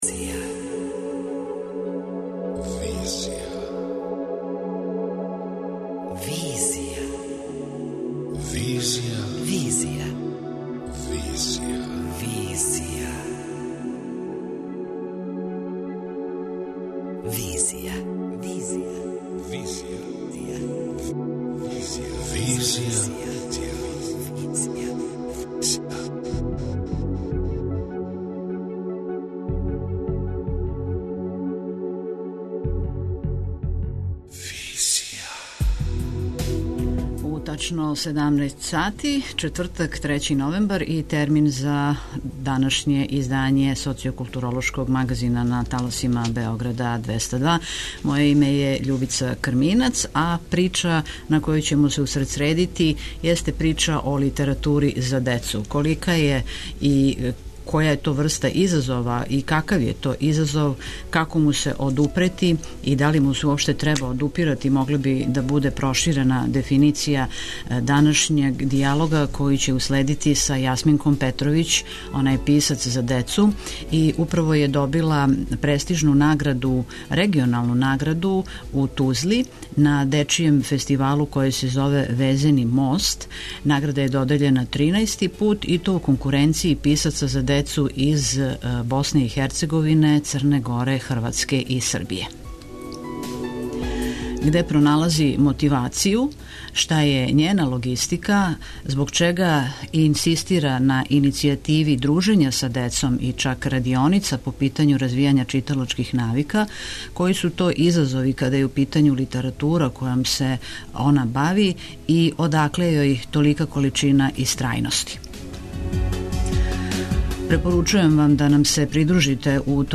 преузми : 27.24 MB Визија Autor: Београд 202 Социо-културолошки магазин, који прати савремене друштвене феномене.